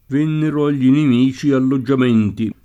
inimico [ inim & ko ]